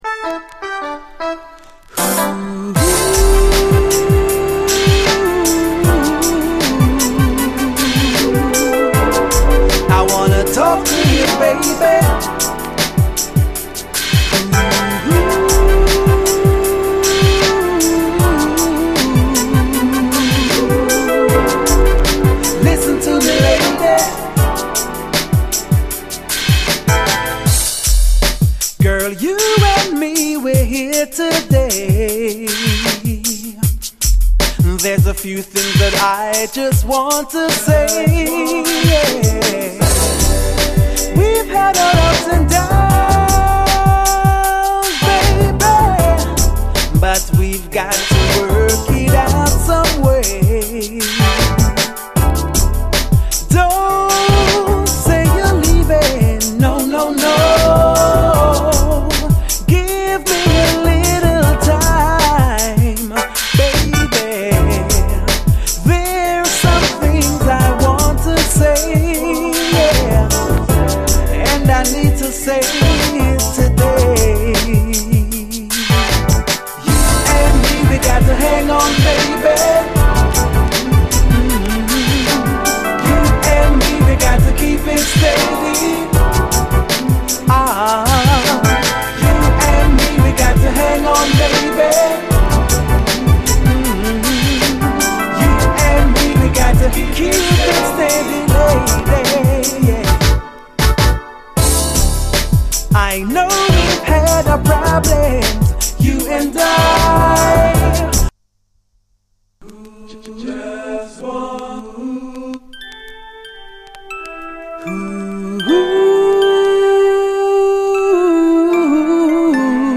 UKストリート・ソウル